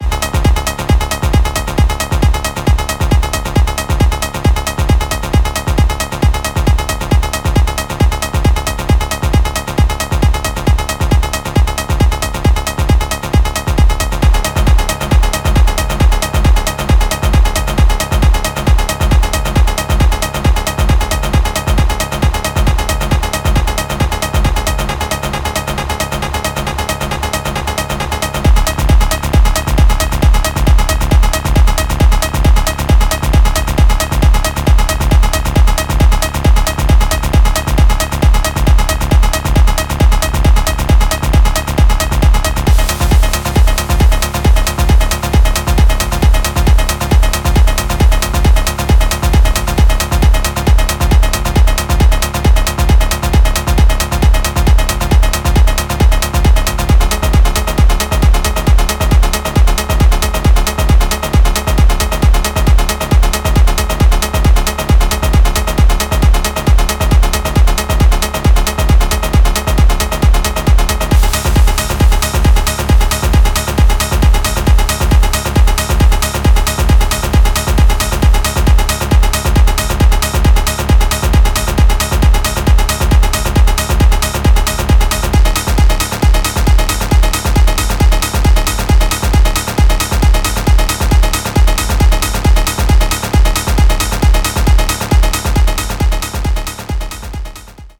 研ぎ澄まされた音響センス、パワフルなグルーヴ、流石の完成度です。